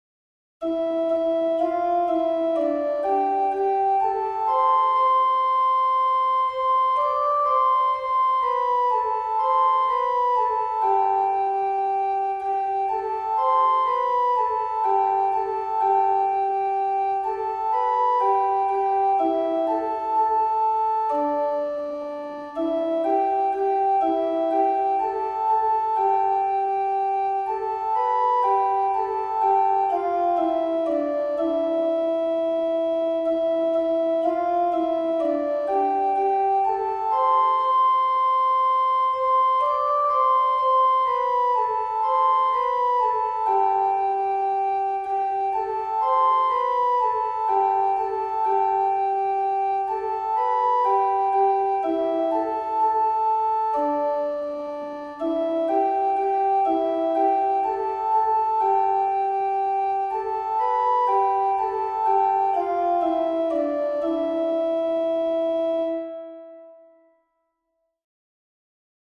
Gregorian Plainchant for piano